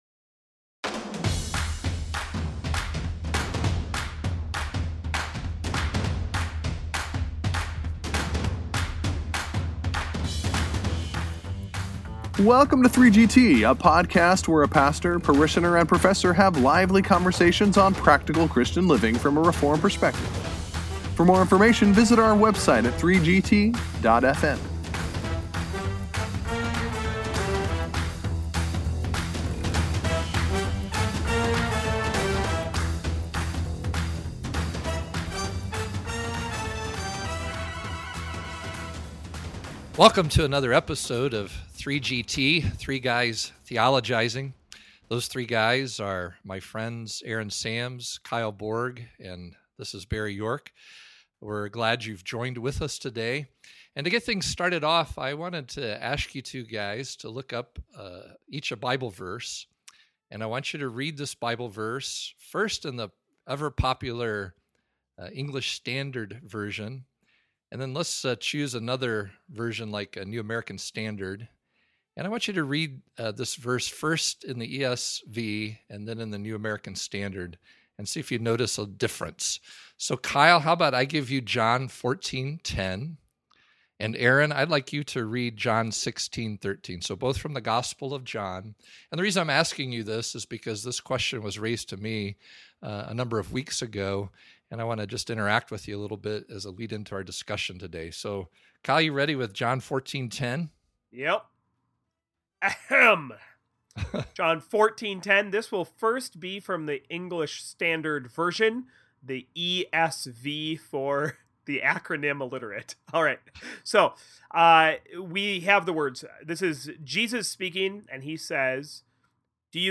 From there they launch into a discussion of the recent debates regarding the Trinity and the teaching referred to as the Eternal Subordination of the Son (ESS). Does the ESV Study Bible promote this teaching?
We’re not sure, but at least our onsite parishioner ends the show with some interesting applications!